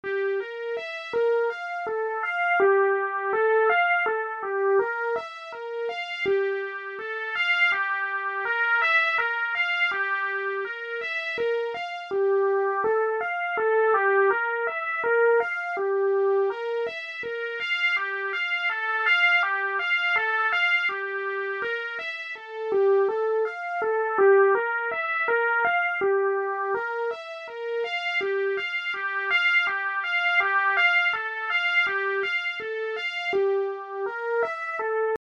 Nijal-03 est un morceau en Sol Dorien :
La partie commune est jouée à la croche (1/2 temps) :
Cette méthode est redoutable pour créer des nappes éthérées et instables qui pourraient être facilement intégrables dans des compositions électros plus conventionnelles.